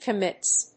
/kʌˈmɪts(米国英語)/